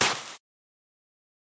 farlands_step_sand.3.ogg